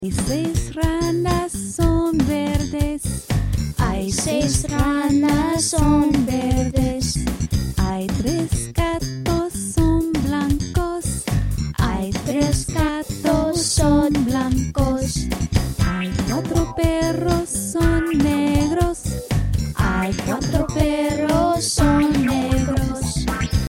Spanish Song Lyrics and Sound Clip